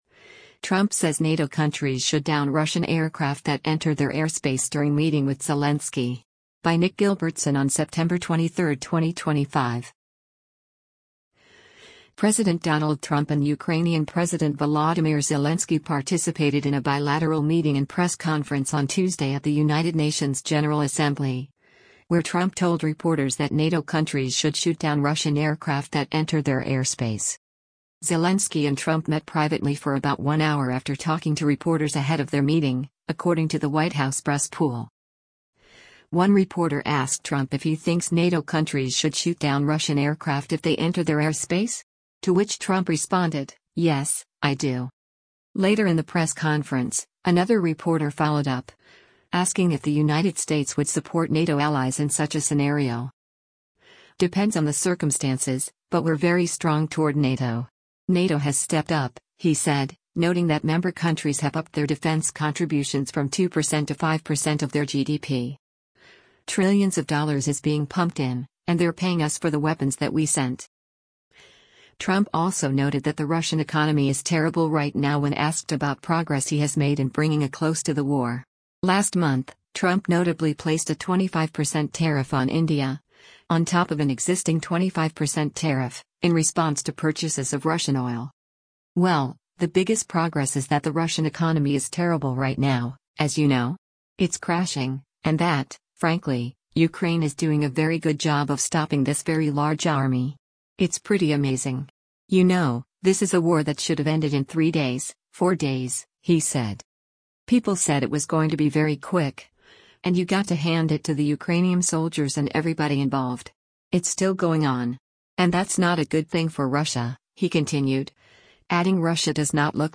President Donald Trump and Ukrainian President Volodymyr Zelensky participated in a bilateral meeting and press conference on Tuesday at the United Nations General Assembly, where Trump told reporters that NATO countries should shoot down Russian aircraft that enter their airspace.
One reporter asked Trump if he thinks “NATO countries should shoot down Russian aircraft if they enter their airspace?” to which Trump responded, “Yes, I do.’